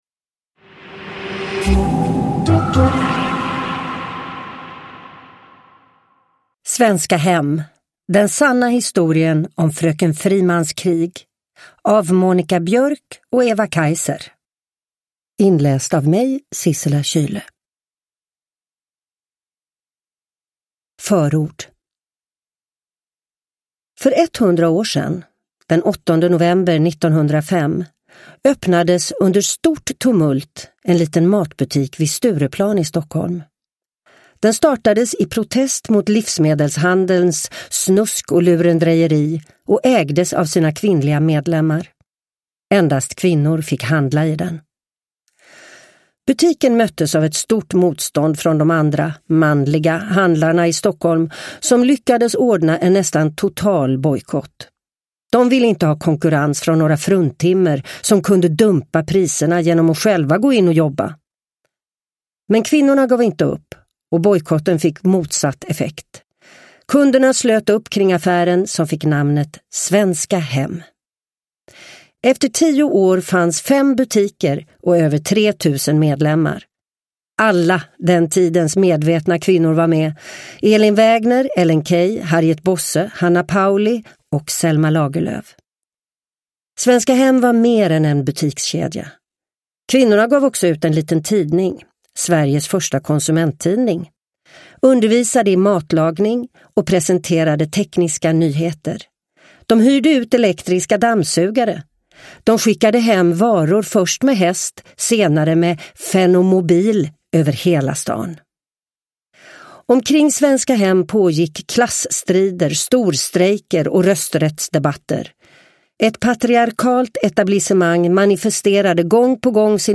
Sissela Kyle läser historien om kvinnorättskämpen Anna Whitlock, hennes medarbetare och deras kamp för den kooperativa matbutiken Svenska hem vid Stureplan i Stockholm. I början av 1900-talet, en tid när kvinnor varken hade rösträtt eller pengar, blev butiken trots allt en enastående framgångssaga.
Uppläsare: Sissela Kyle